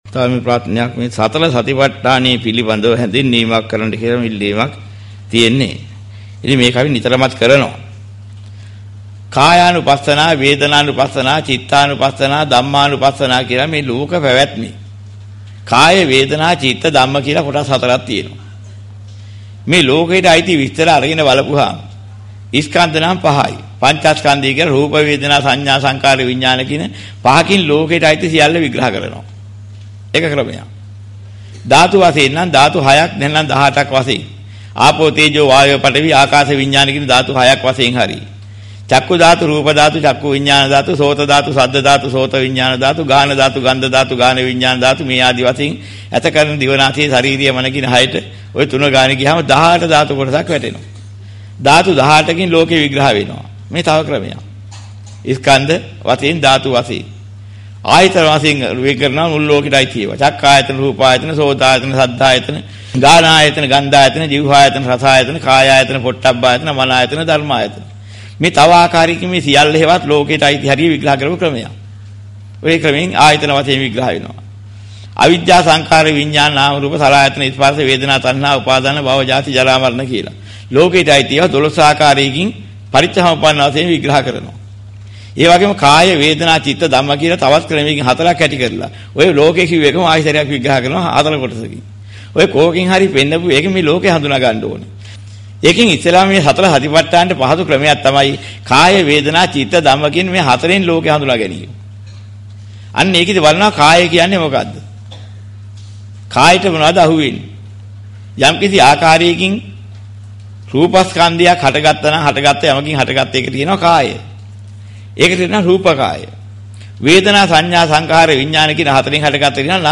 මෙම දේශනාවේ සඳහන් වන ධර්ම කරුණු: